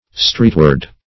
Meaning of streetward. streetward synonyms, pronunciation, spelling and more from Free Dictionary.
[1913 Webster] The Collaborative International Dictionary of English v.0.48: Streetward \Street"ward\, a. Facing toward the street.